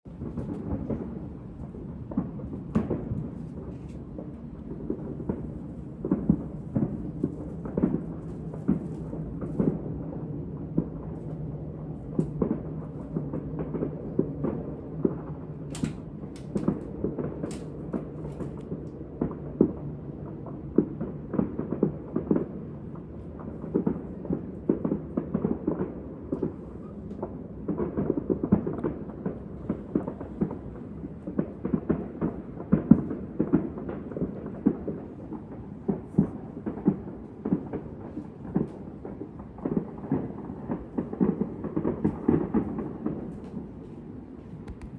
Even our tiny town has its own fireworks.